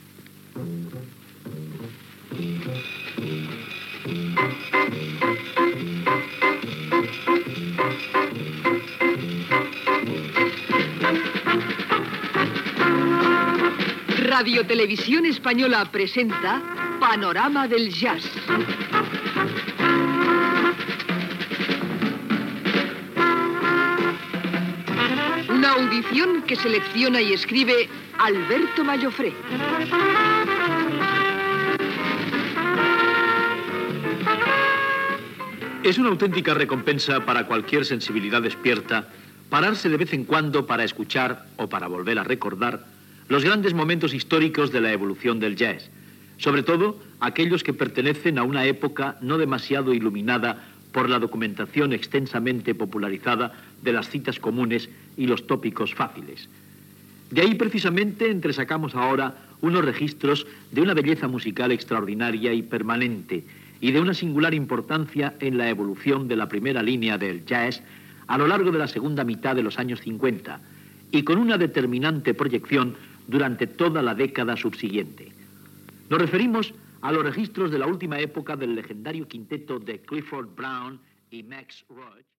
Careta del programa i presentació inicial